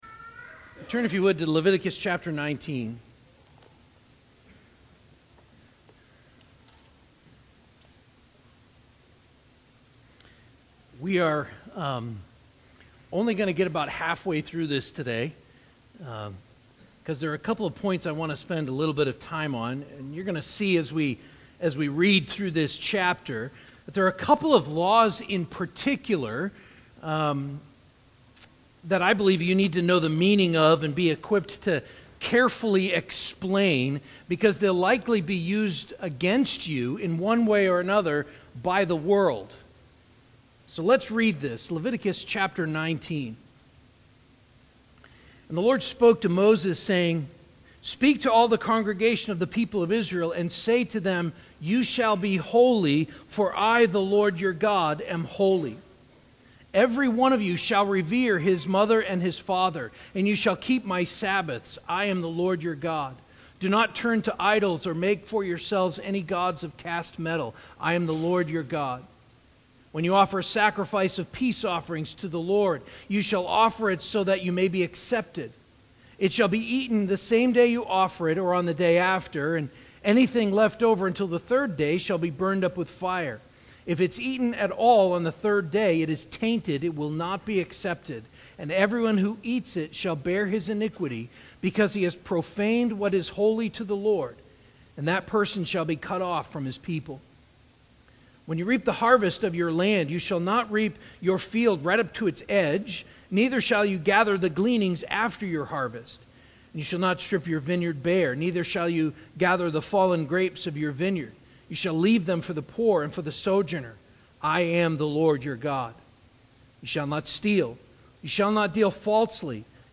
6.18.23-sermon-CD.mp3